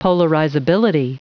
Prononciation du mot polarizability en anglais (fichier audio)
Prononciation du mot : polarizability